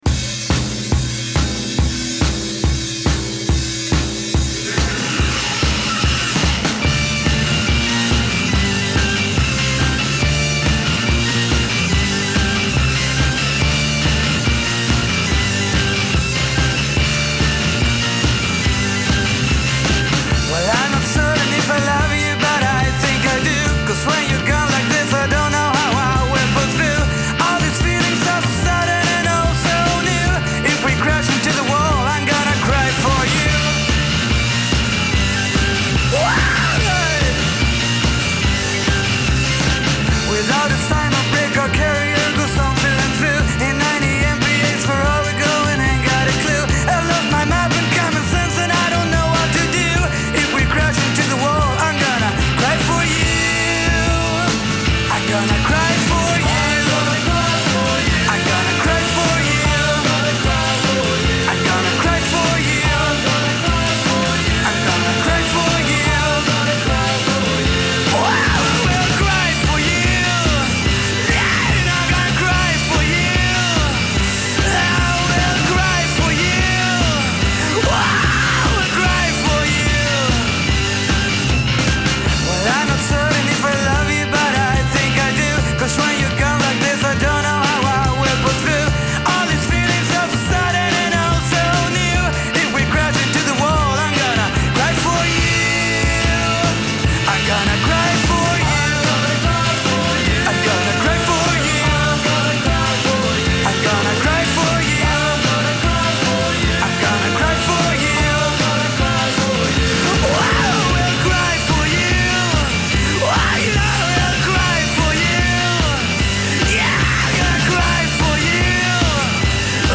So there you go. 18 blasts of prime ‘60s Mod Beat’.